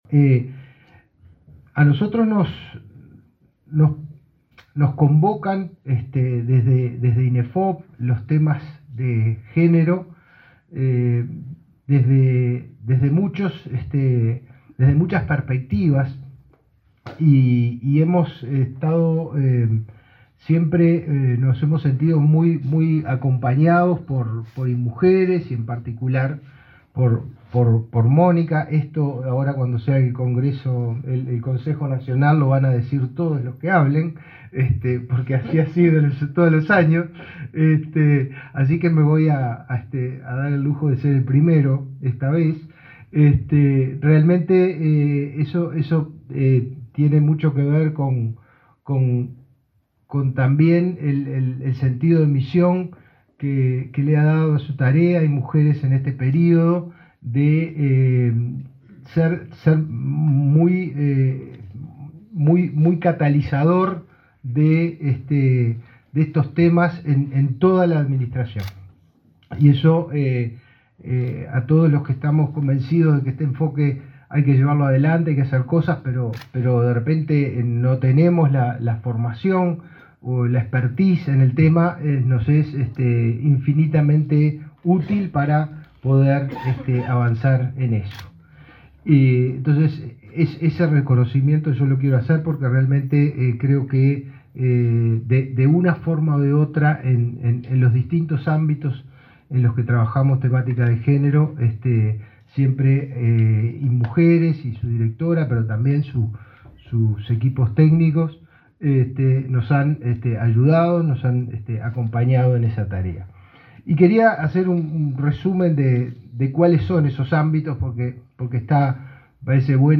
Palabra de autoridades en acto de Inefop e Inmujeres
Palabra de autoridades en acto de Inefop e Inmujeres 18/09/2023 Compartir Facebook X Copiar enlace WhatsApp LinkedIn El director del Instituto Nacional de Empleo y Formación Profesional (INEFOP), Pablo Darscht, y la directora de Inmujeres, Mónica Bottero, informaron a la prensa sobre el trabajo de ambas instituciones, en especial en los proyectos orientados a la autonomía económica de las mujeres.